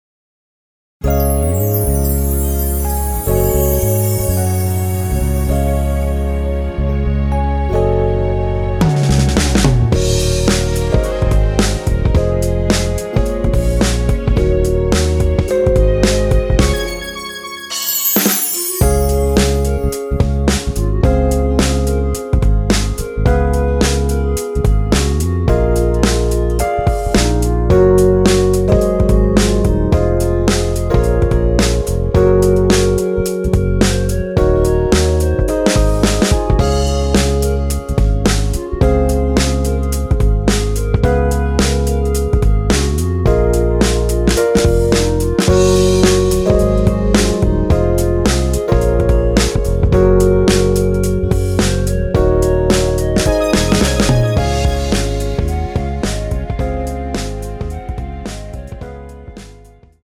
원키에서(-4)내린 멜로디 포함된 MR입니다.(미리듣기 확인)
Db
앞부분30초, 뒷부분30초씩 편집해서 올려 드리고 있습니다.